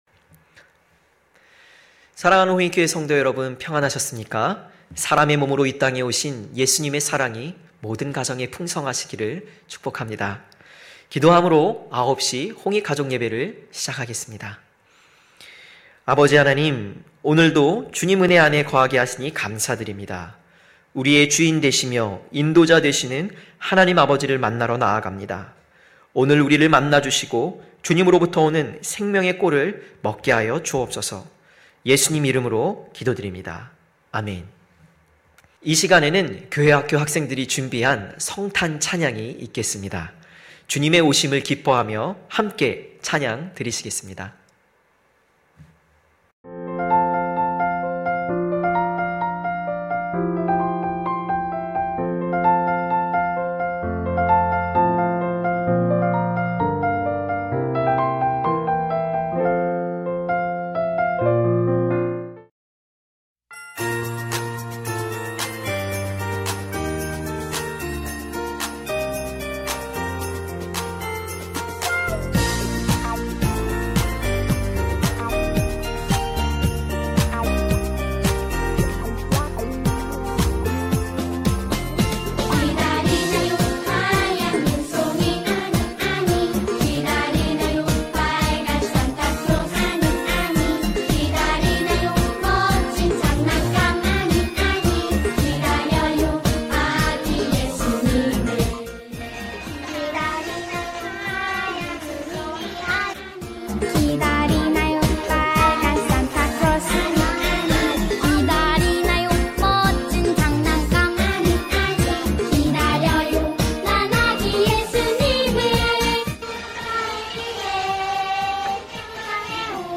9시 홍익가족예배 성탄(12월21일).mp3